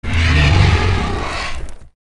Вы можете слушать и скачивать рычание, крики, шум крыльев и другие эффекты в высоком качестве.